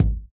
This is bizarre…some of the samples are playing back "dull" from the Sampler.